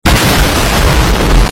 boommmm! :D boom nek eat sound effects free download